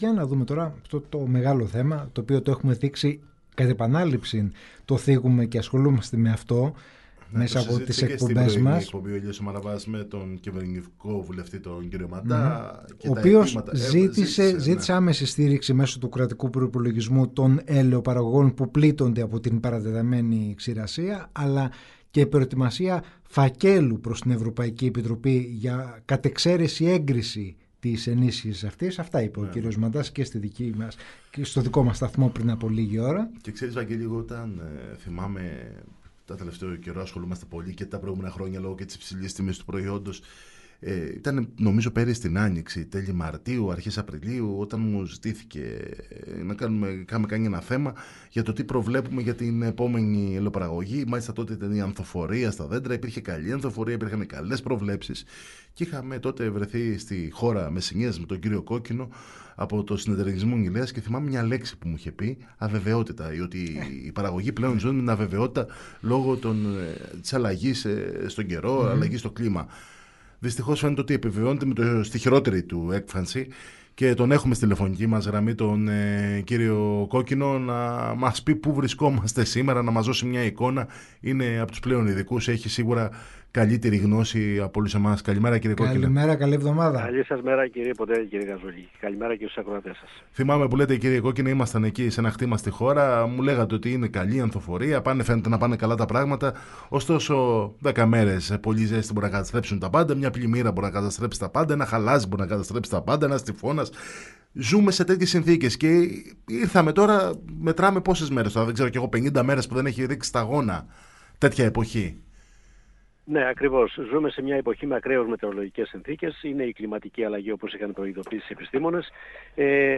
Για την δραματική κατάσταση που έχει δημιουργηθεί στη φετινή ελαιοπαραγωγή, εξαιτίας της παρατεταμένης ανομβρίας και ξηρασίας, μίλησε στο ραδιόφωνο της ΕΡΤ Καλαμάτας και στην εκπομπή “Πρωινό στον αέρα” σήμερα το πρωί